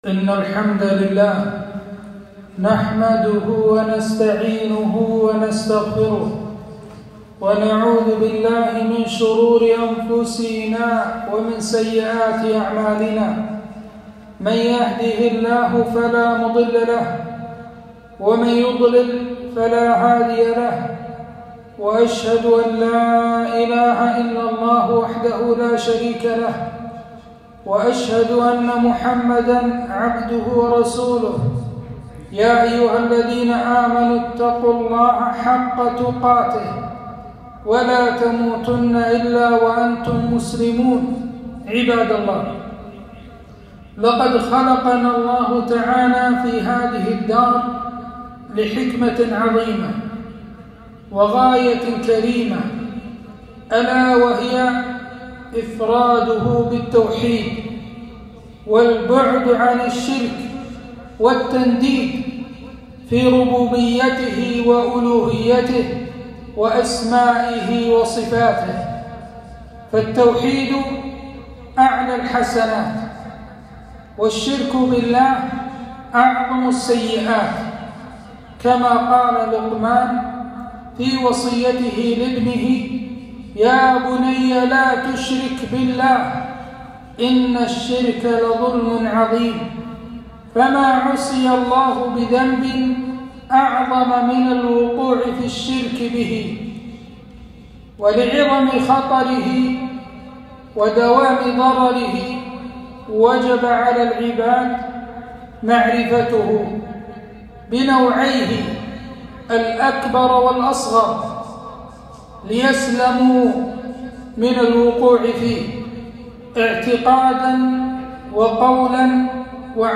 خطبة - الشرك الأصغر حقيقته وأنواعه وأحكامه